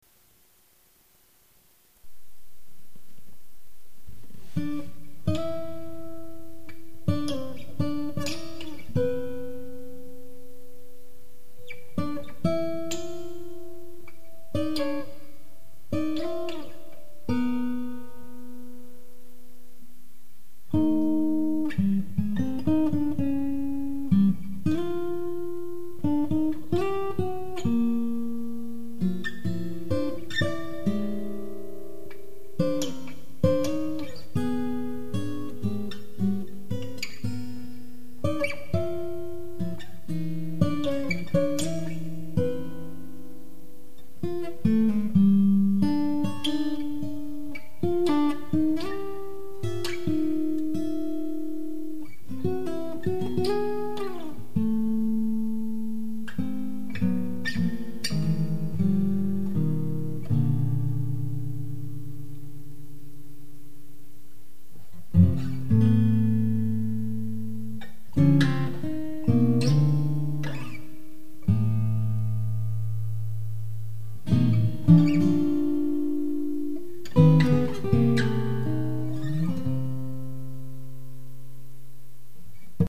6- & 12-string acoustic guitars, Fretless guitar, Mandolin
6-string acoustic guitar